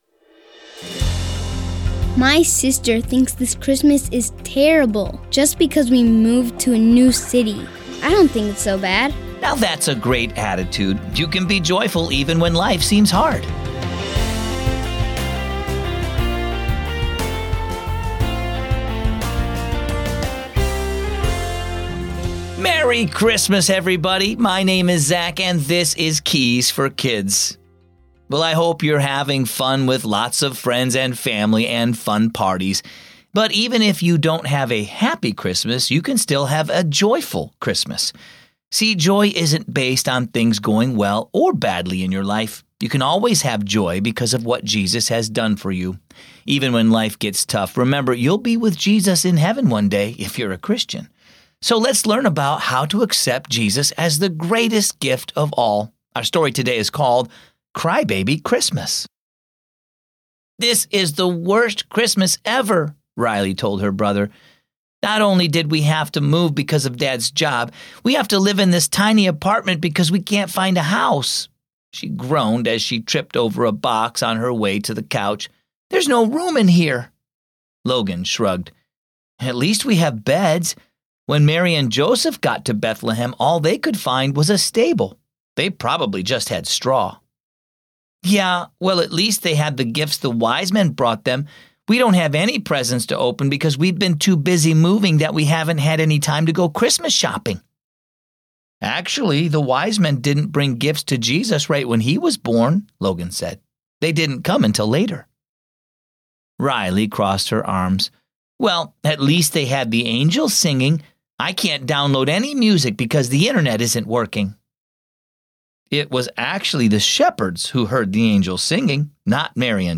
Keys for Kids is a daily storytelling show based on the Keys for Kids children's devotional.